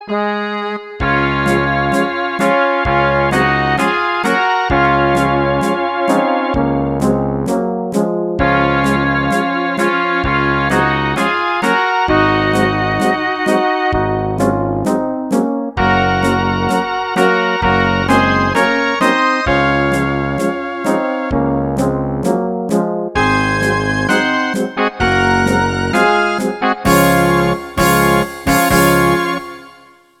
Rubrika: Národní, lidové, dechovka
- smuteční pochod